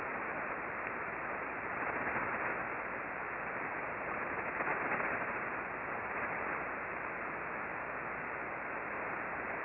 We used the Icom R-75 HF Receiver tuned to 20.408 MHz (LSB). The antenna was an 8-element log periodic antenna pointed 100 degrees true (no tracking was used).
Click here for a recording of the bursting at 1034